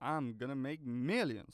Voice Lines
I am gonna make millions.wav